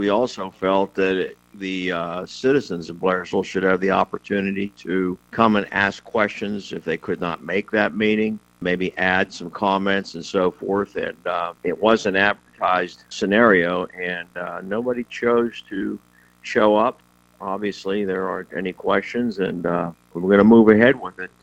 Councilman Ab Dettorre said while the council attended and listened to the presentation, they decided to take it back to their council to see if Blairsville citizens had any concerns.